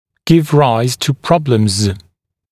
[gɪv raɪz tə ‘prɔbləmz][гив райз ту ‘проблэмз]вызывать проблемы